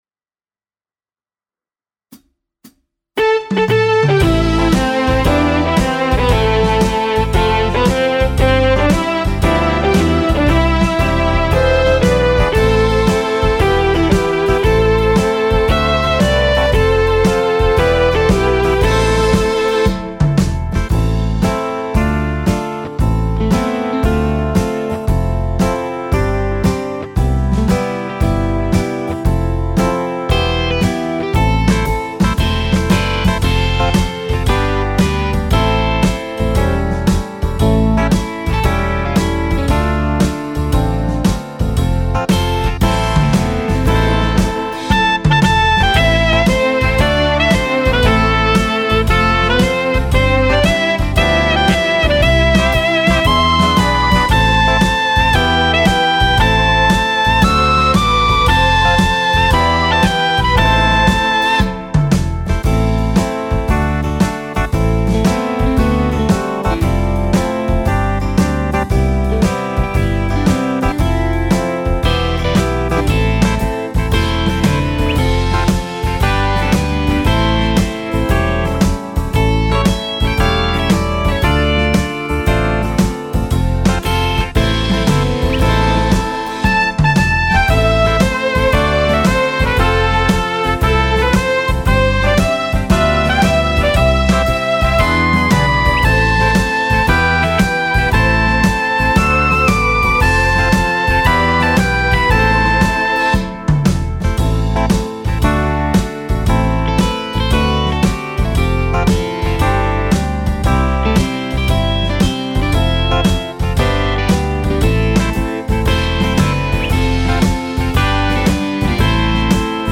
Tone Nam (Am) / Nữ (F#m)
•   Beat  01.